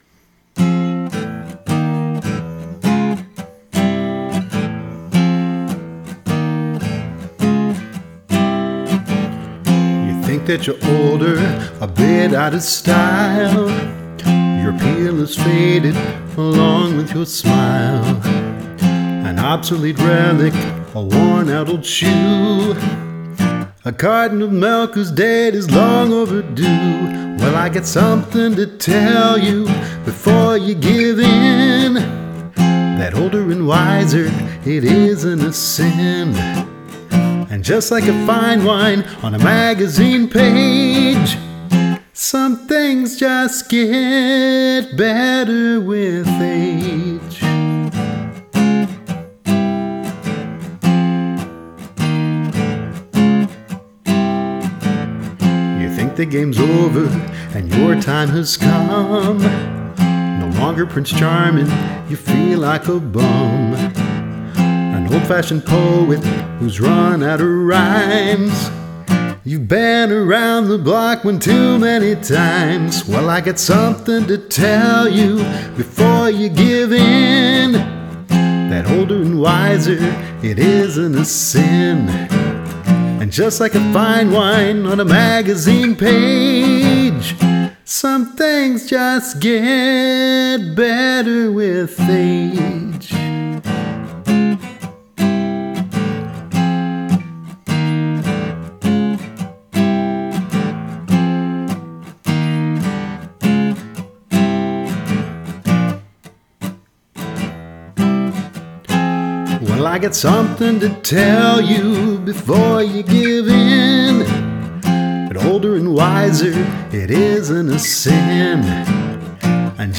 Genre: Singer-Songwriter.